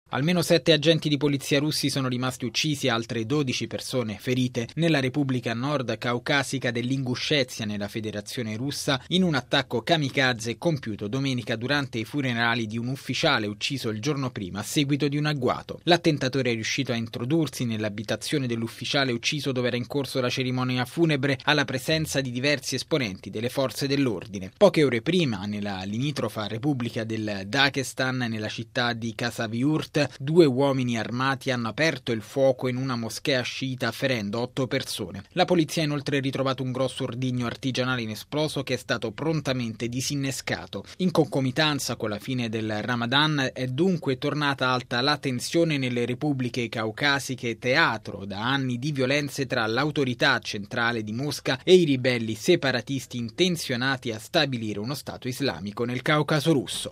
Nello scorso fine settimana due distinti attentati hanno colpito il Daghestan e l’Inguscezia, lasciando sul terreno otto morti e oltre 20 feriti. Il servizio